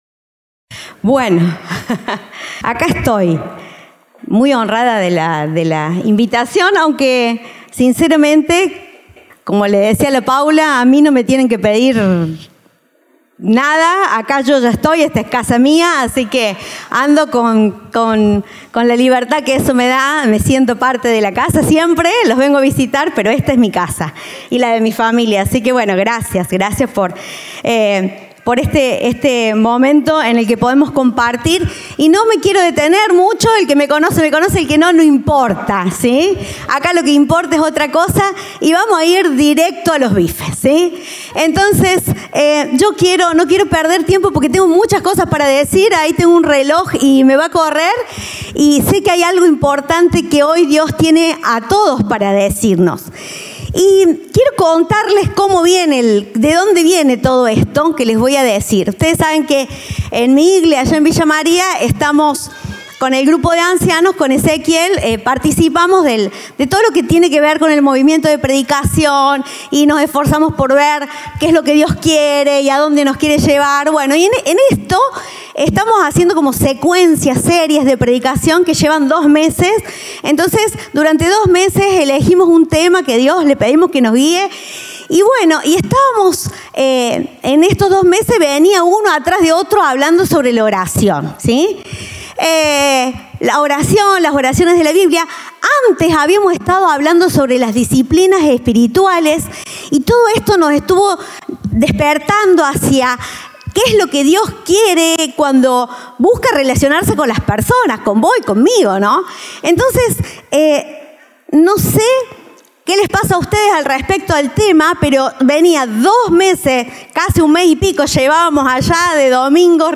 Compartimos el mensaje del Domingo 11 de Agosto de 2024.